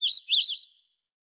11_Tweet.ogg